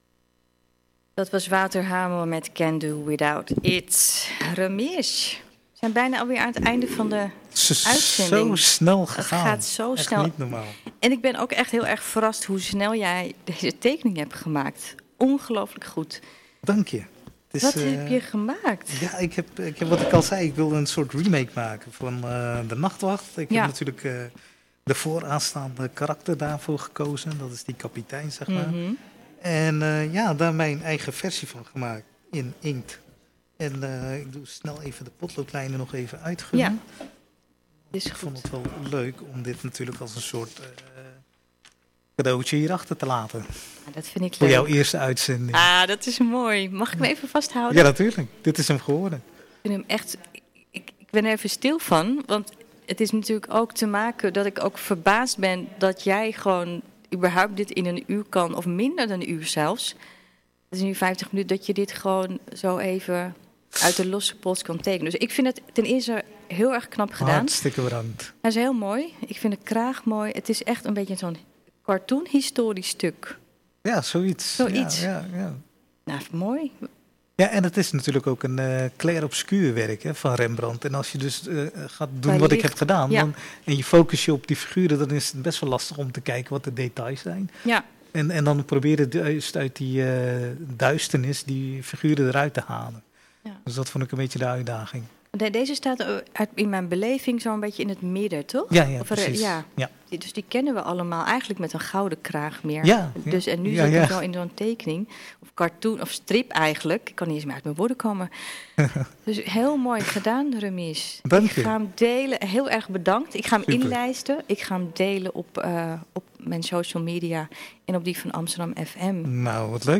De studio van AmsterdamFM is elke vrijdagmiddag van 15 – 16 uur de werkplek van een kunstenaar.